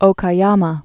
(ōkä-yämä)